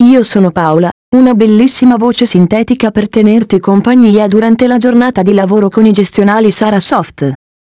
E' la pronuncia di messaggi con voce umana sintetica, ad esempio "Benvenuto e buon lavoro" all'apertura del programma, "Confermi la cancellazione ?" in caso di richiesta di cancellazione di un dato da un archivio, eccetera.
testo pronunciato con la voce "Paola"
esempio-paola.wav